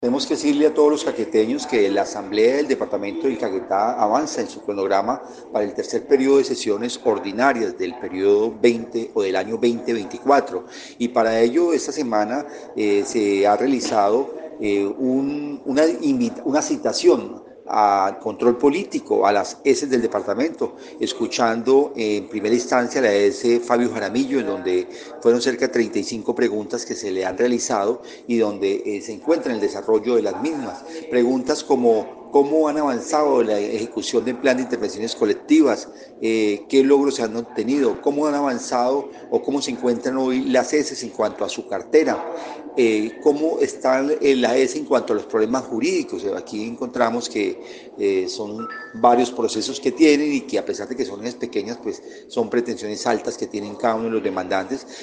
Richard Gutiérrez Cruz, diputado por Cambio Radical, y actual presidente de la corporación, explicó que, para el caso de las empresas sociales del estado, hoy el turno fue para la Fabio Jaramillo, donde se quiere conocer todo el accionar financiero y administrativo junto a la contratación.